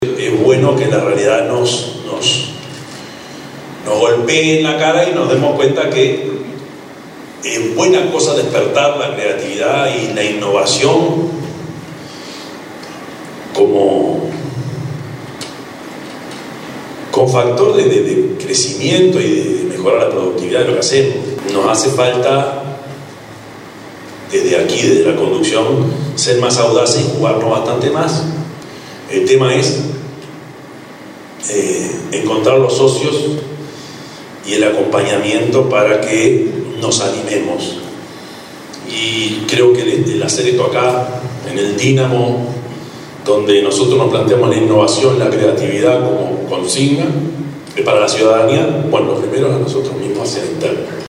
prof_yamandu_orsi_intendente_de_canelones.mp3